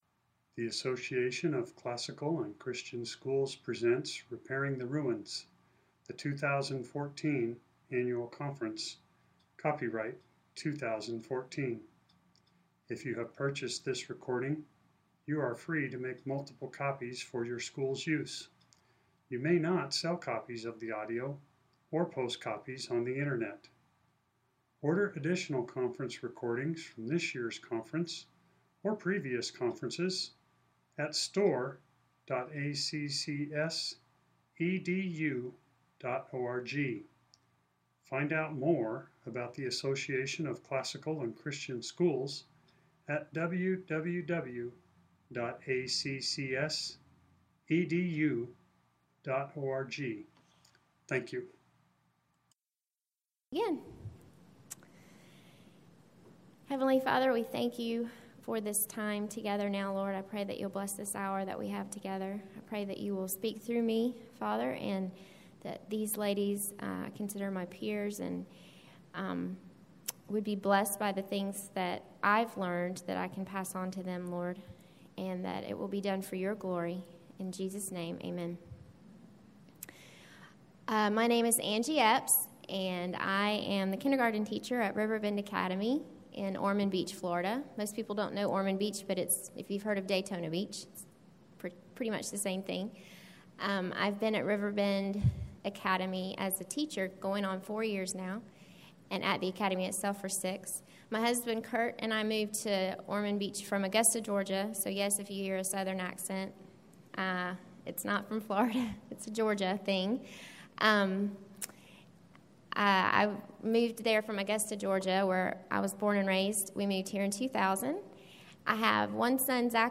2014 Workshop Talk | 1:04:49 | All Grade Levels, Literature
The Association of Classical & Christian Schools presents Repairing the Ruins, the ACCS annual conference, copyright ACCS.